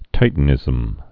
(tītn-ĭzəm)